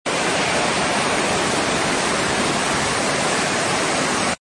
Rain with thunder
描述：Heavy rain with a thunder. Useful for ambience sound. Recordad with a Zoom H5.
标签： Rain Sound Ambience Thunder Heavy
声道立体声